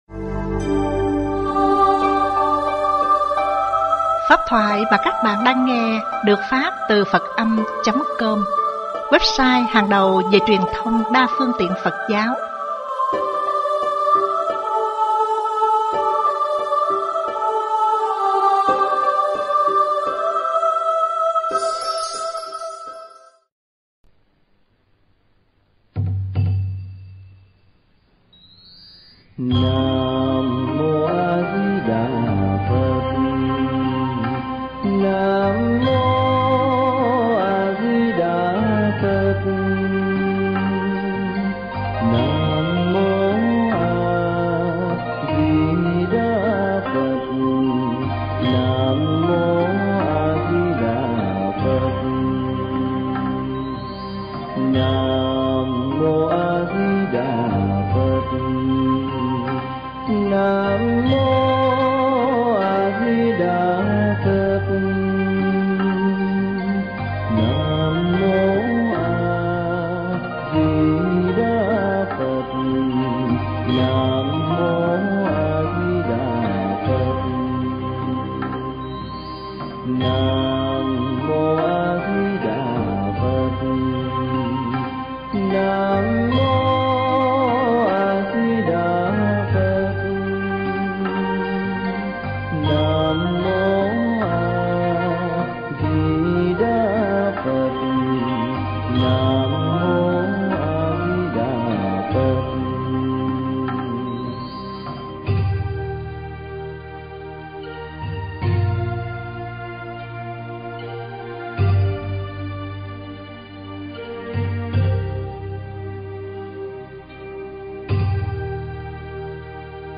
Nghe Mp3 thuyết pháp Ba Hạng Người Làm Phật Sự